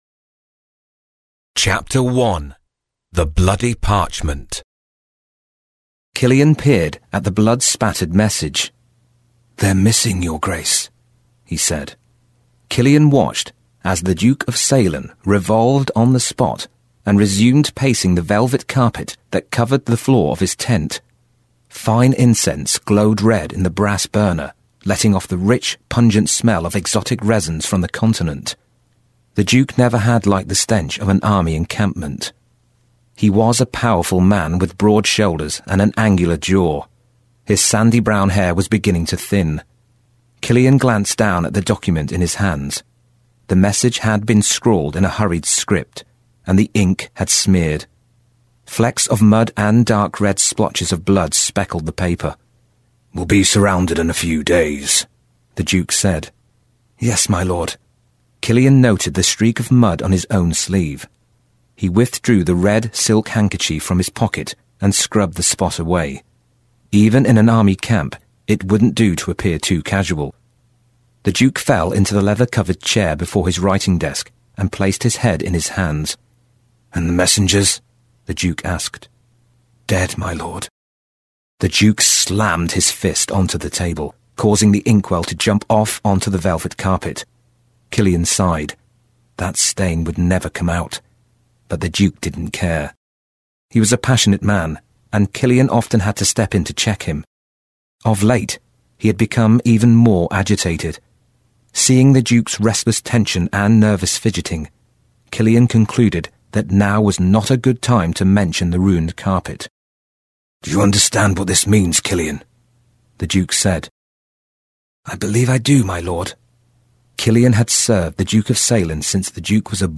Audio Book Sample, Chapter One The Bloody Parchment